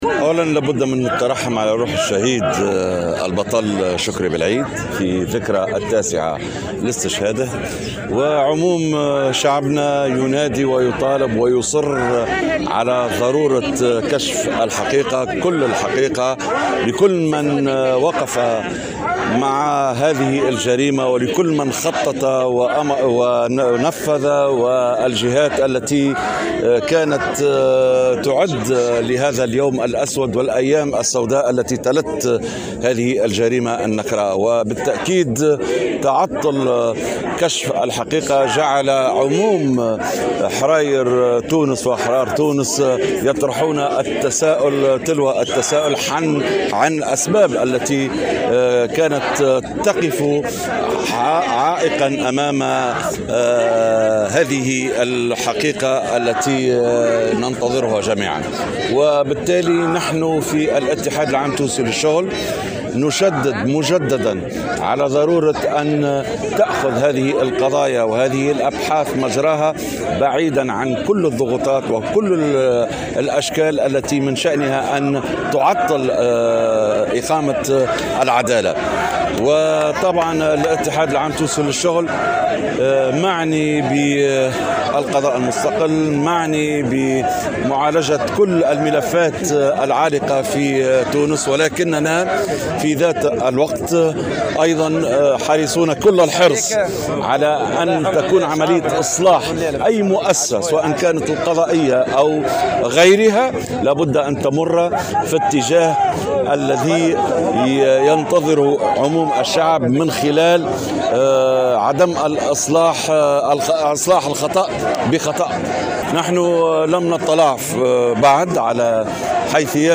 وأكد الشفي في تصريح لمراسل الجوهرة اف ام، على هامش مسيرة لإحياء ذكرى استشهاد بلعيد، مساندة اتحاد الشغل لكل مساعي إصلاح القضاء، مضيفا أن المنظمة لم تطلع بعد على حيثيات قرار رئيس الجمهورية بحل المجلس الأعلى للقضاء.